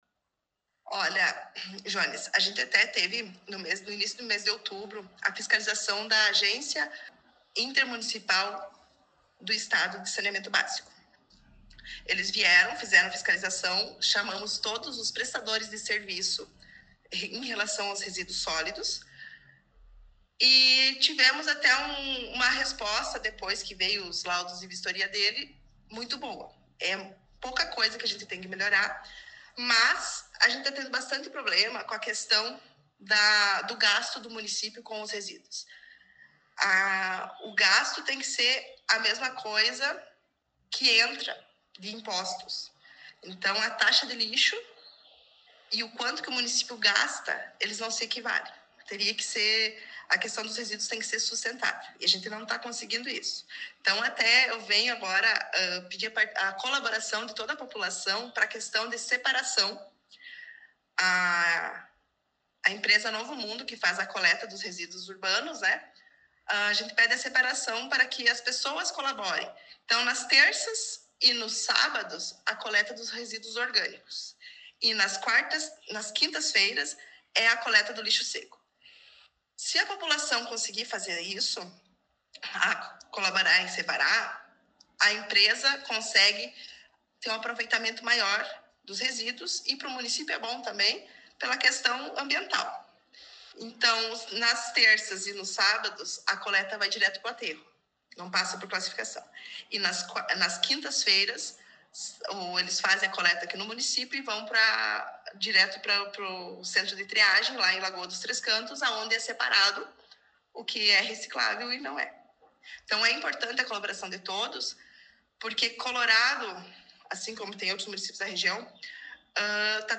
Secretária Municipal do Meio Ambiente e Saneamento Básico concedeu entrevista